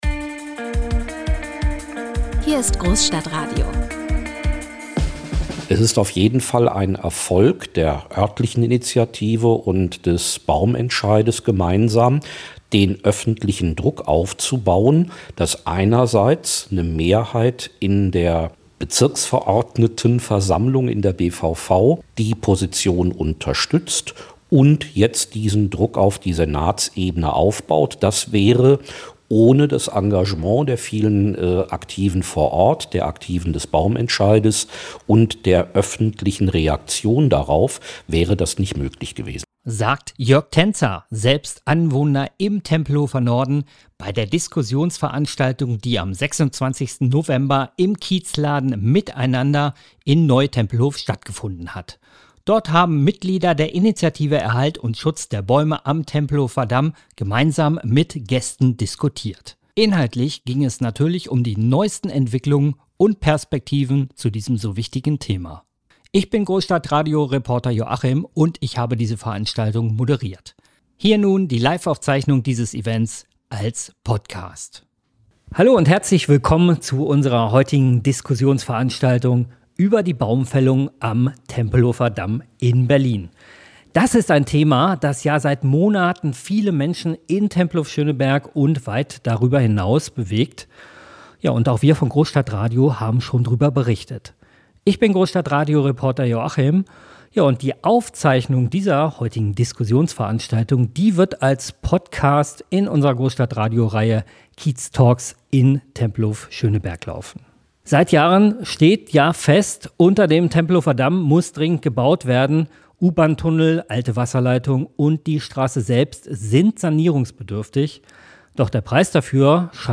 In diesem live aufgezeichneten Podcast diskutieren Anwohnende und Mitglieder von Bürgerinitiativen über die geplanten Baumfällungen und neue Perspektiven für ihr Handeln.
Höre Stimmen vor Ort zu Handlungsoptionen, Strategien und Chancen für den Kiez.